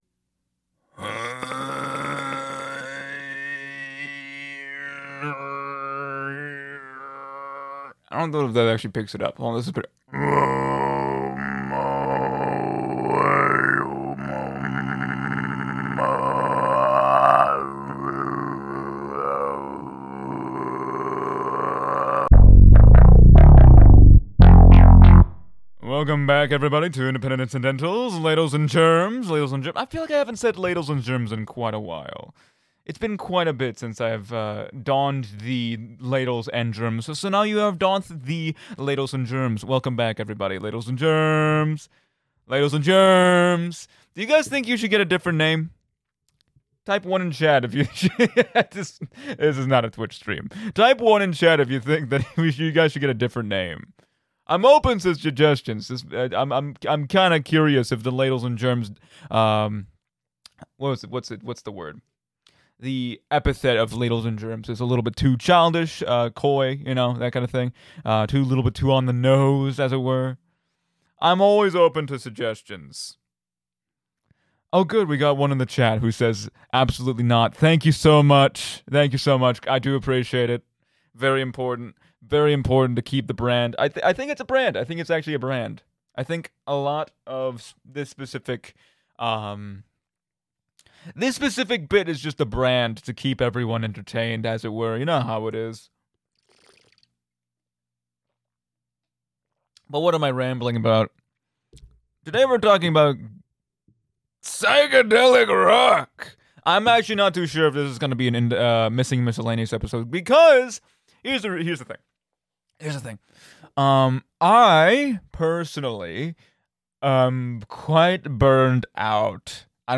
Independent Incidentals – Ep. 32: Psych Rock – Radio Laurier